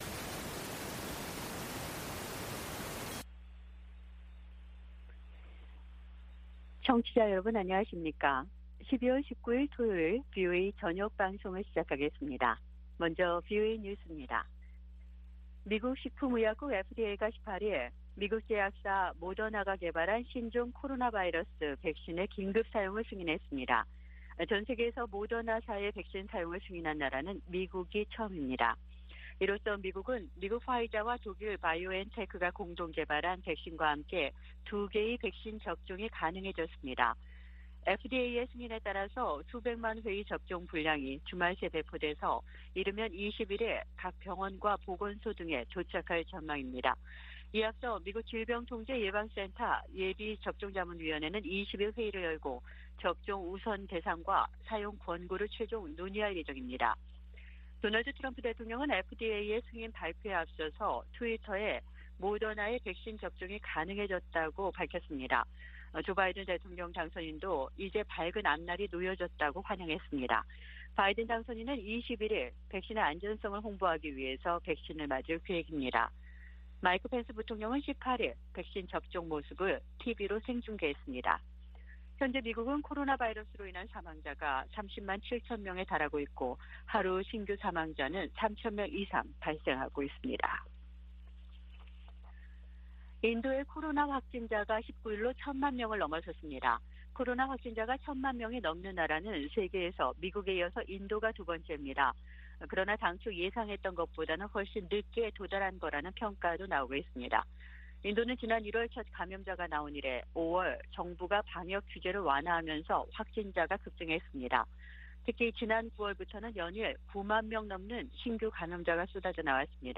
VOA 한국어 방송의 토요일 오후 프로그램 1부입니다.